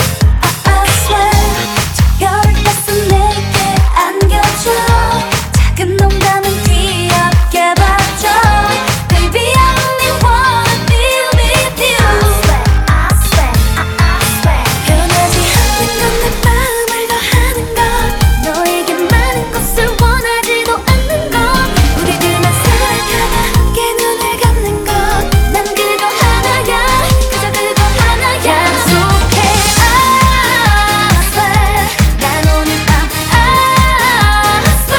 Жанр: Поп музыка / Рок / Танцевальные